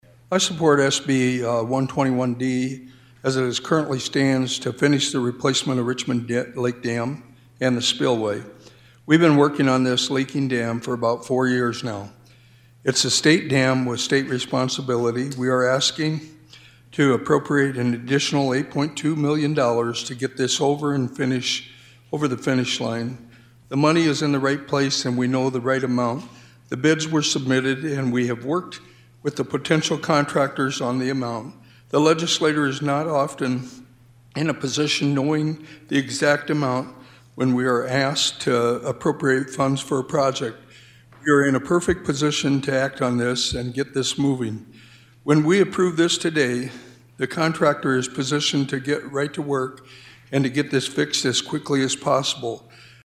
SD Senate: